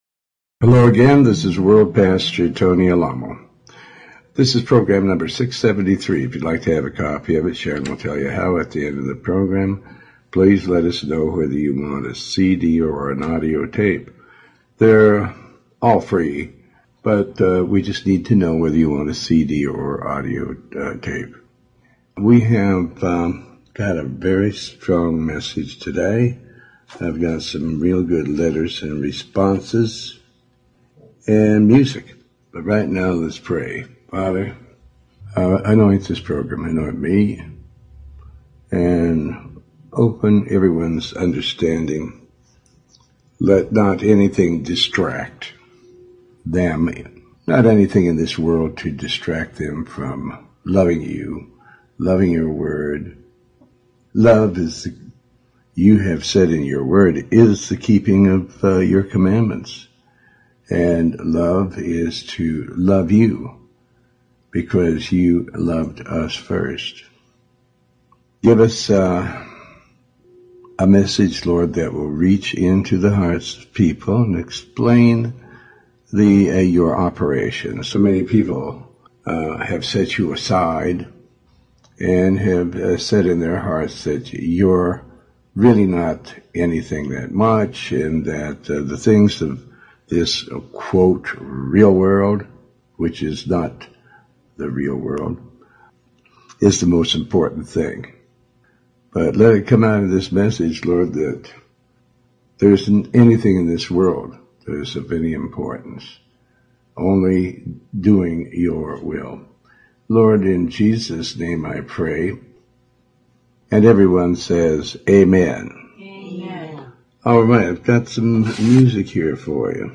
Talk Show Episode
Show Host Pastor Tony Alamo